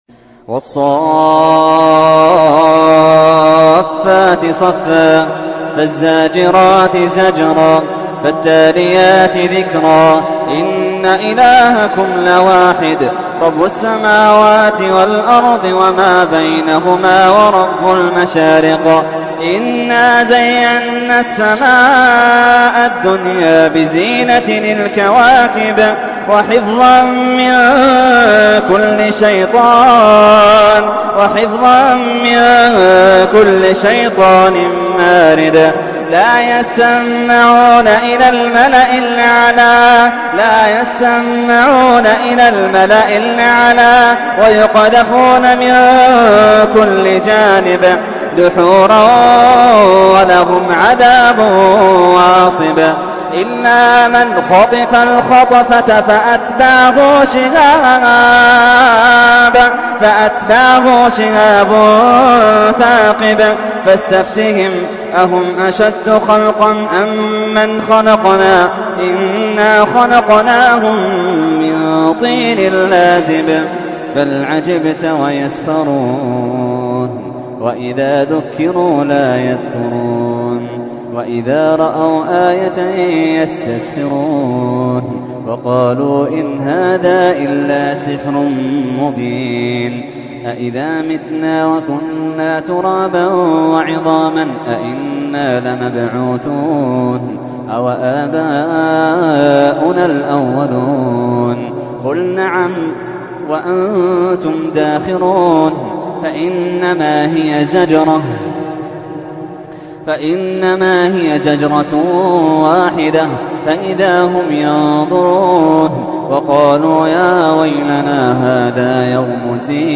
تحميل : 37. سورة الصافات / القارئ محمد اللحيدان / القرآن الكريم / موقع يا حسين